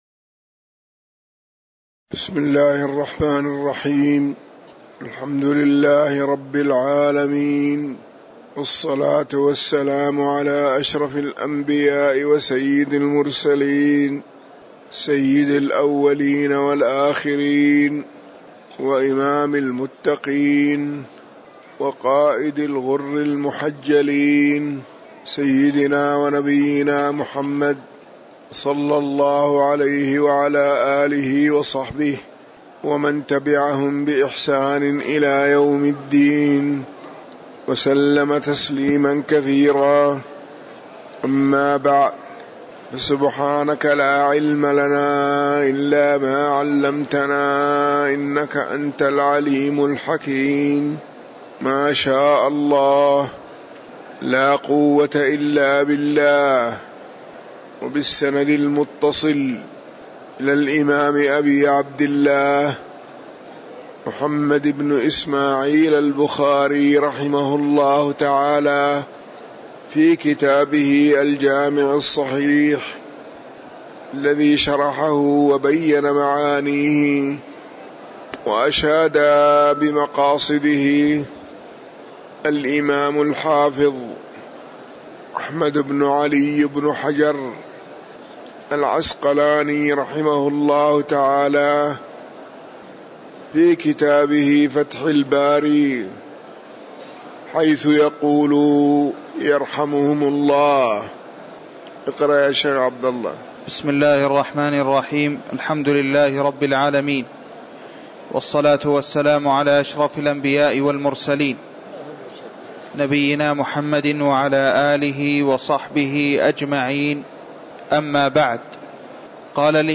تاريخ النشر ٢٢ ربيع الأول ١٤٤١ هـ المكان: المسجد النبوي الشيخ